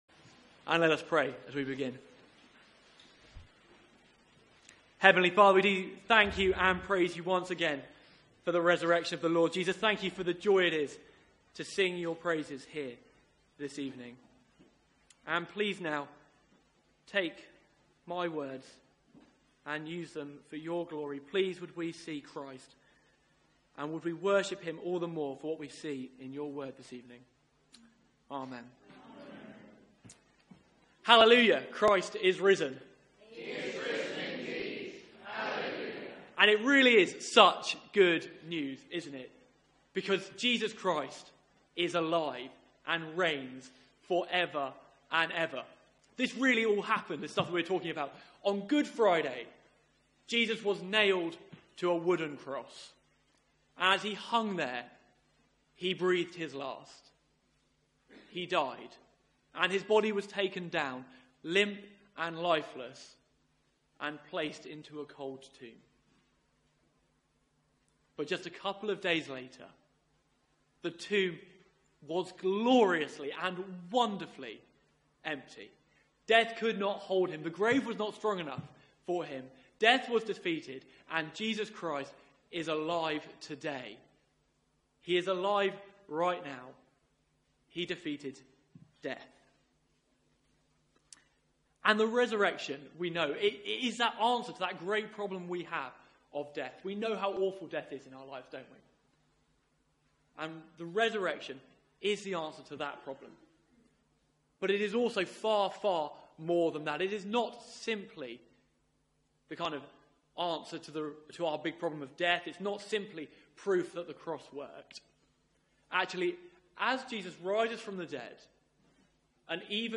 Media for 6:30pm Service on Sun 16th Apr 2017 18:30 Speaker
Easter Theme: Easter Praise Sermon Search the media library There are recordings here going back several years.